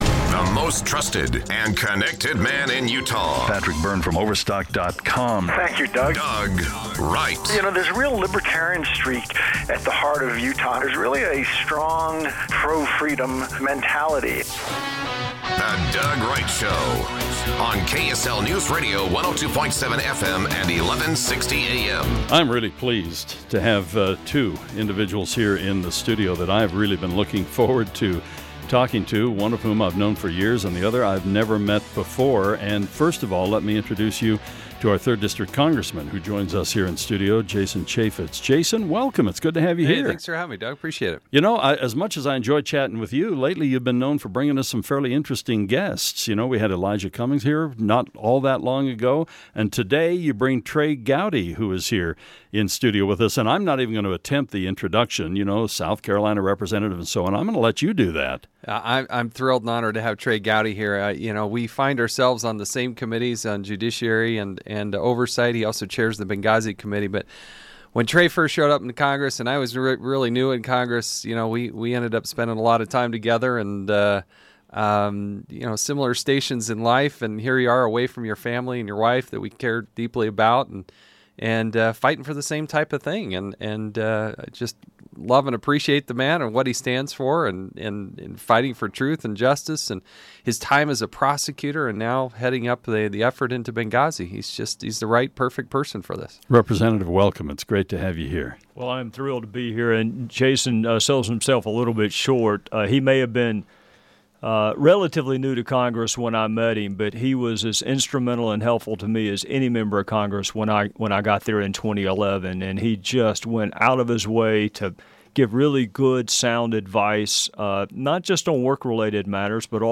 Rep. Trey Gowdy, a former federal prosecutor, recounts the challenges he faces in the investigation of Hillary Clinton's private emails. He's joined by Congressman Jason Chaffetz.